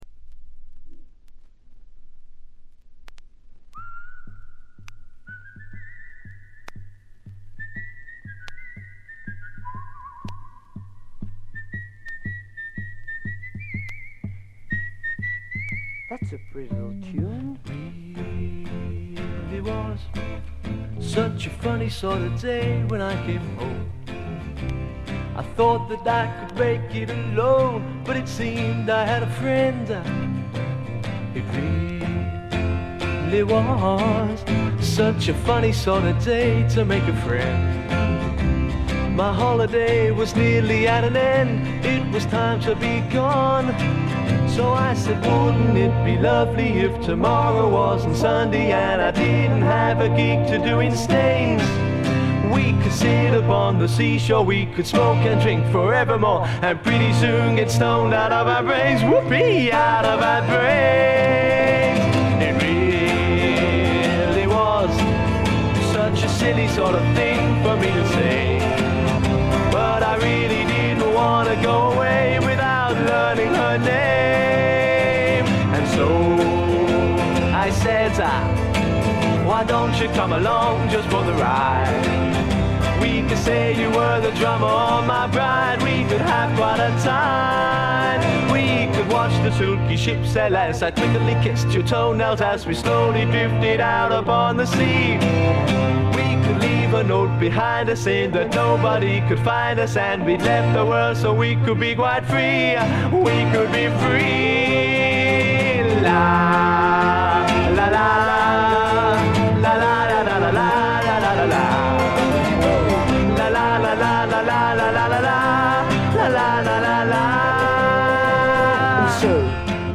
A面冒頭少しチリプチ。これ以外はわずかなノイズ感のみ。
いまさらいうまでもないピュアで美しい英国製ドリーミーフォークの逸品です。
ドリーミー・フォーク名盤。
試聴曲は現品からの取り込み音源です。
Guitar, 12-String Acoustic Guitar, Vocals
Flute